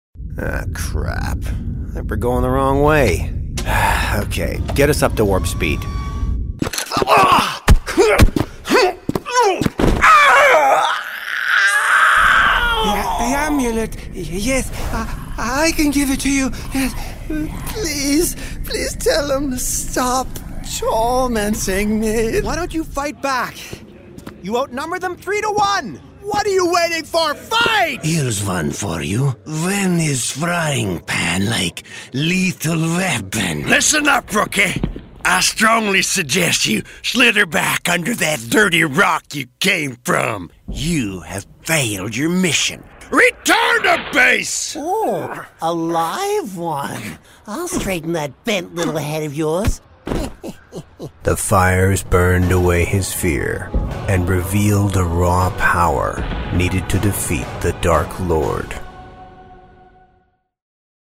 Video Game